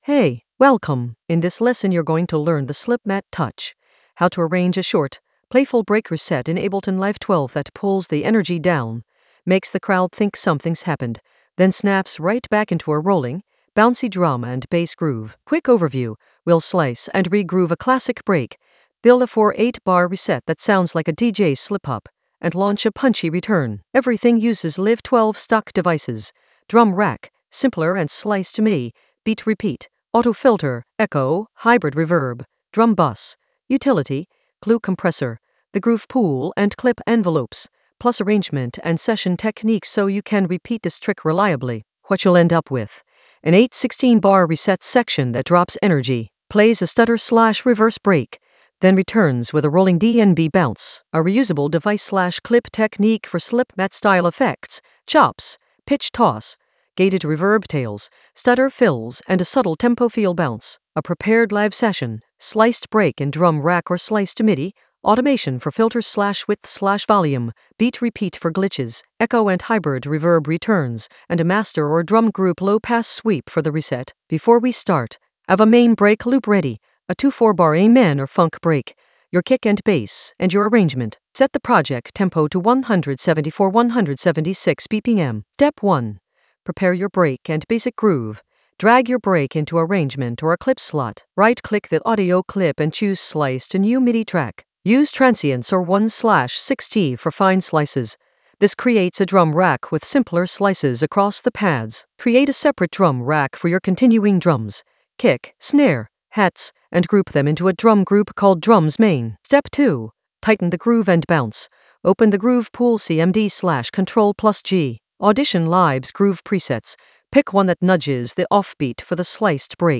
An AI-generated intermediate Ableton lesson focused on Slipmatt touch: arrange a playful break reset in Ableton Live 12 for crowd-moving drum and bass bounce in the Groove area of drum and bass production.
Narrated lesson audio
The voice track includes the tutorial plus extra teacher commentary.